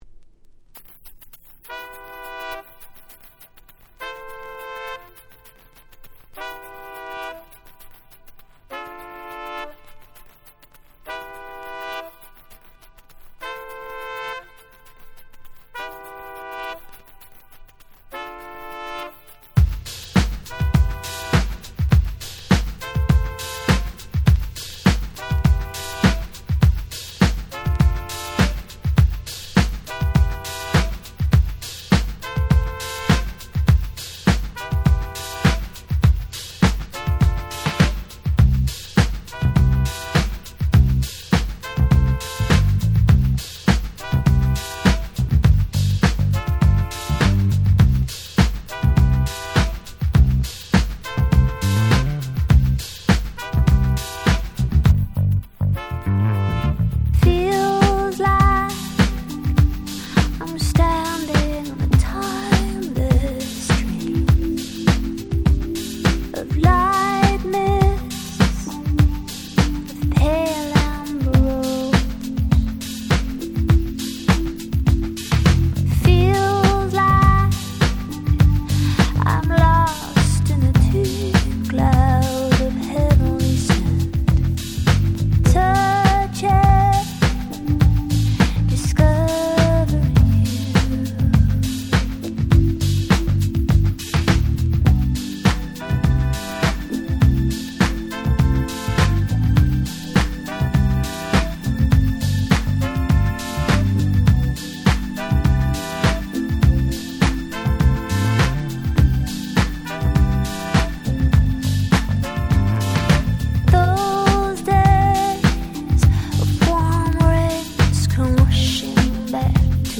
音質もバッチリでめちゃくちゃ使えます！！！